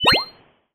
collect_item_03.wav